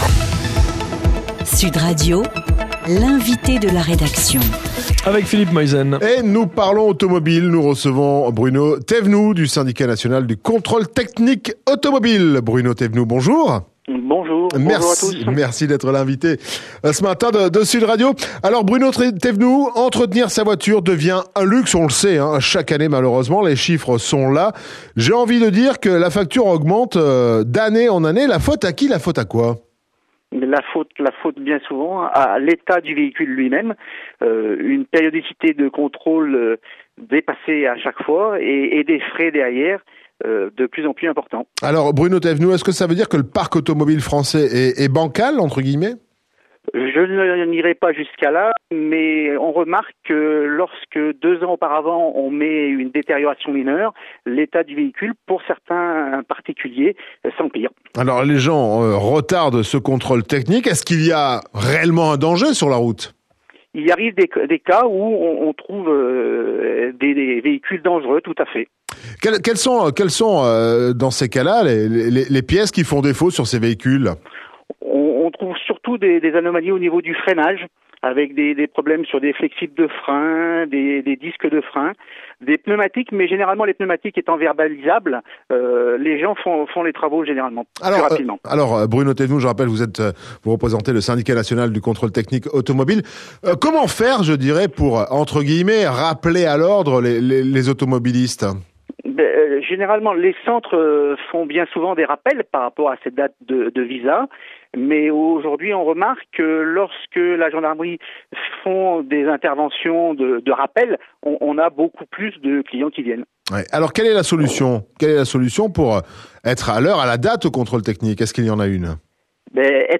Interview SNCTA par Sud Radio le 21-05-13